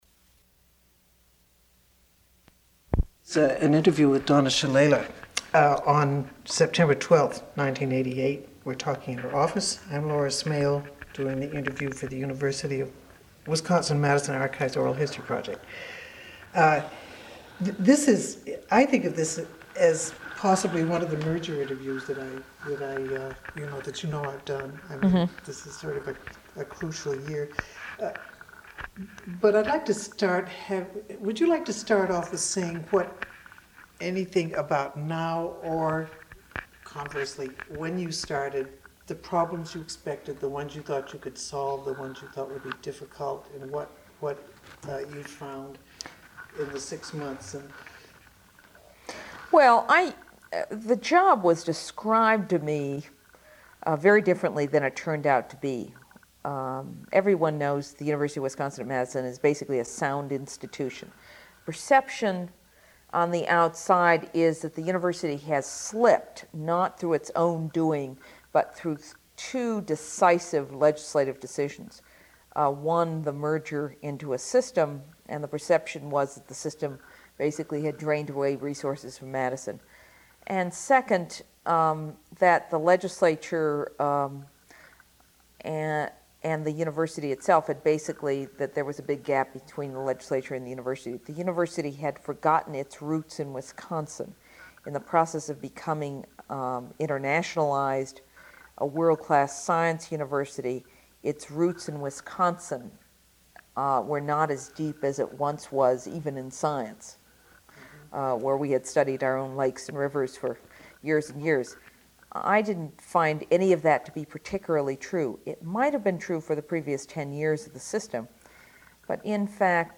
Oral History Interview: Donna Shalala (0357)